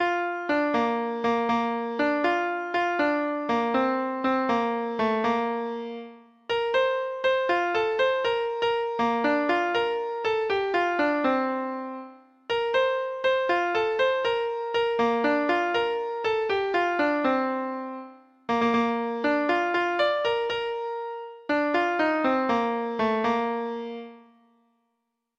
Note: Originally an Irish Street Ballad.
Traditional Music of unknown author.